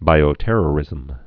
(bīō-tĕrə-rĭzəm)